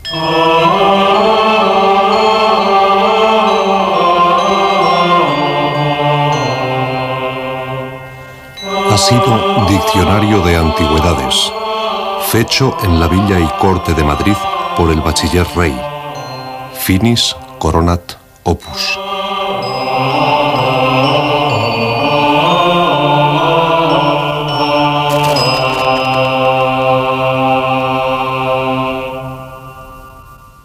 Música i careta de sortida.
FM